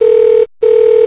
Telefon.wav